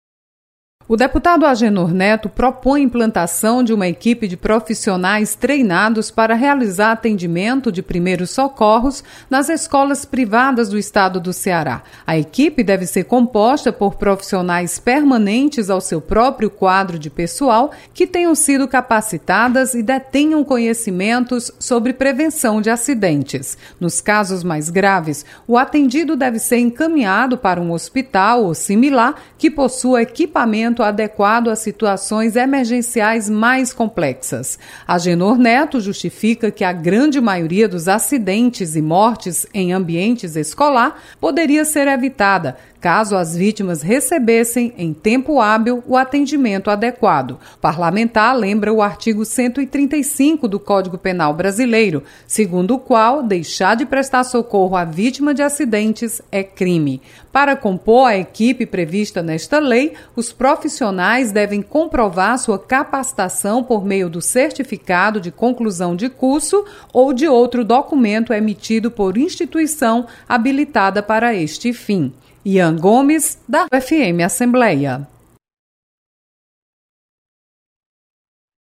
Projeto prevê atendimento de primeiros socorros nas escolas da rede particular. Repórter